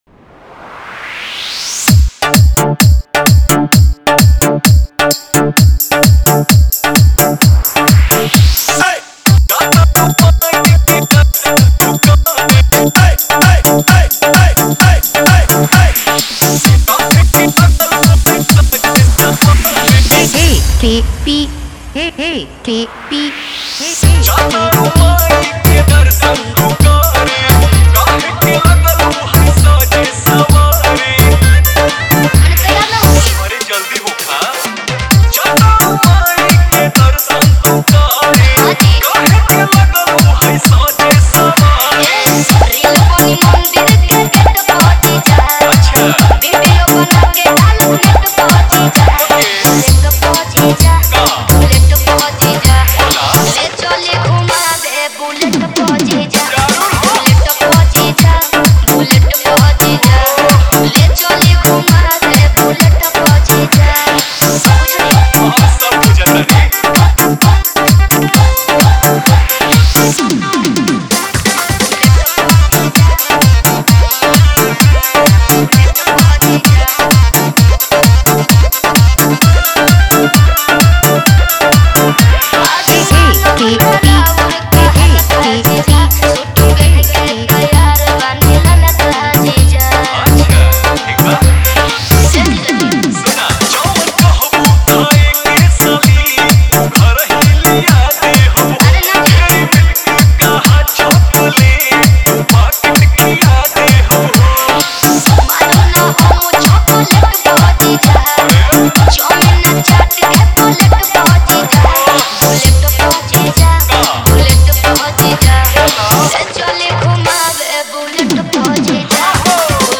Category: Holi Dj Songs 2022